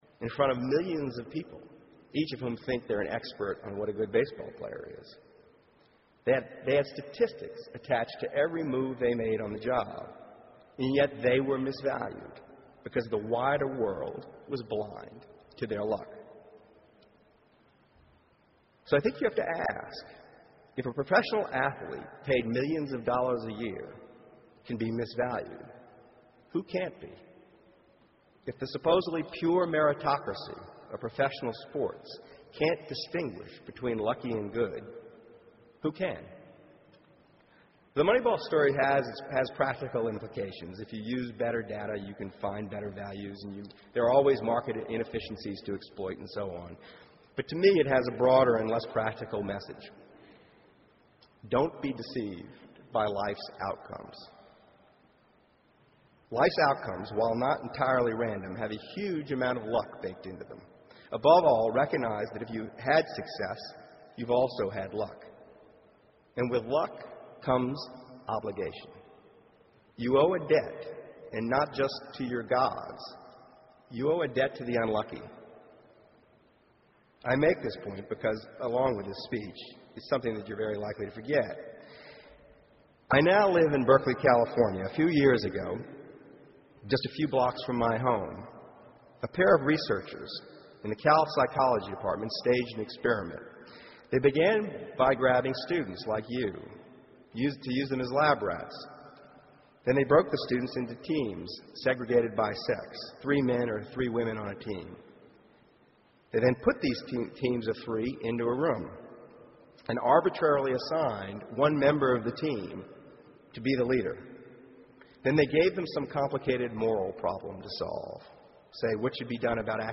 公众人物毕业演讲 第142期:2012年Michael Lewis普林斯顿大学(6) 听力文件下载—在线英语听力室